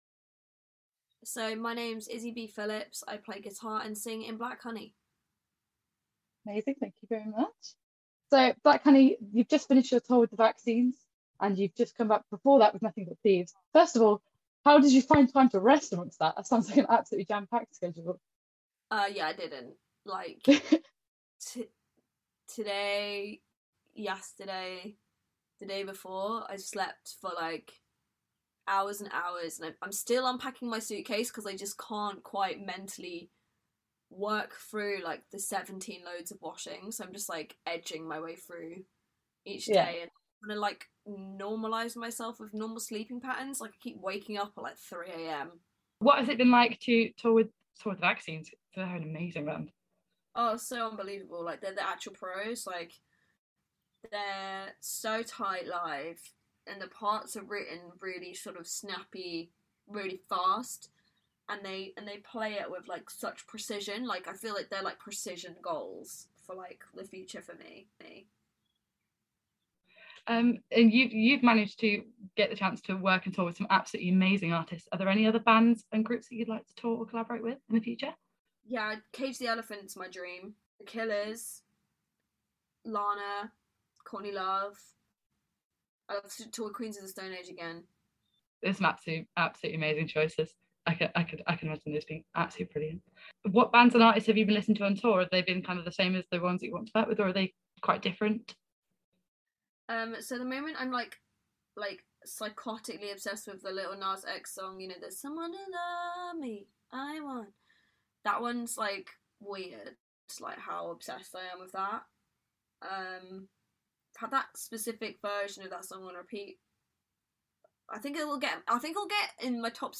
Black Honey Interview